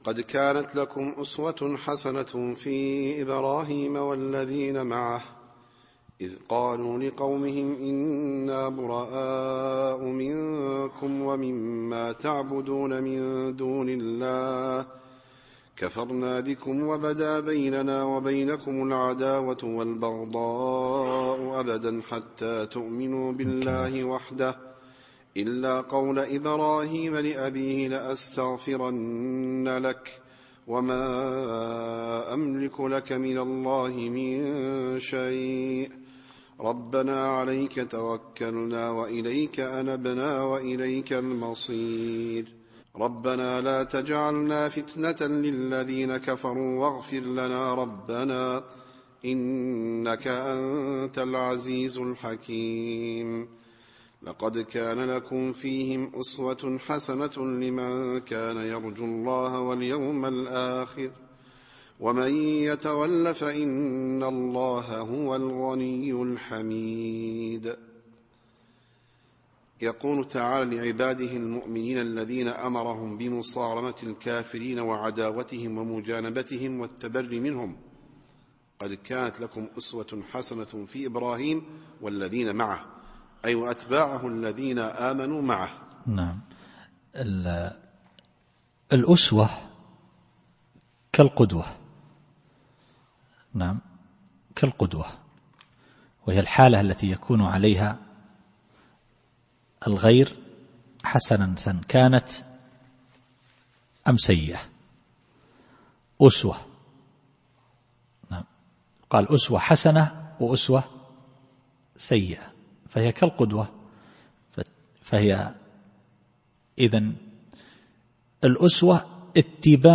التفسير الصوتي [الممتحنة / 4]